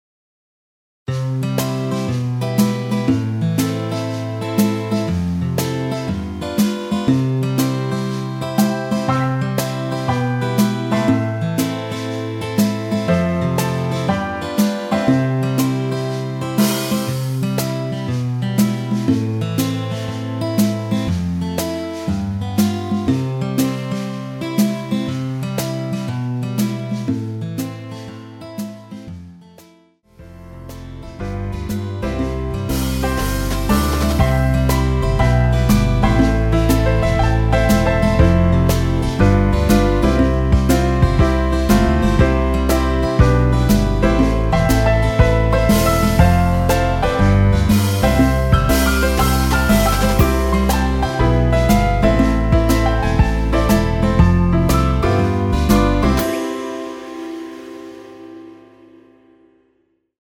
엔딩이 페이드 아웃에 너무 길어서 4마디로 엔딩을 만들었습니다.(미리듣기 참조)
앞부분30초, 뒷부분30초씩 편집해서 올려 드리고 있습니다.